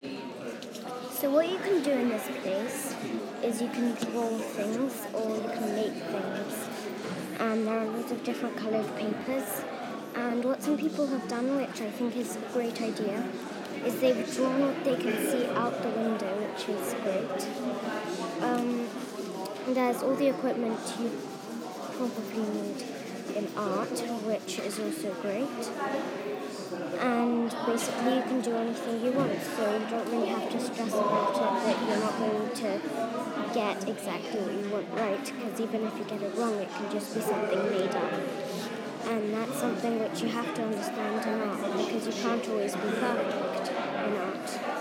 I CAN DO... (Audio Description of space and setting)